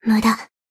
贡献 ） 分类:蔚蓝档案语音 协议:Copyright 您不可以覆盖此文件。
BA_V_Shiroko_Battle_Defense_1.ogg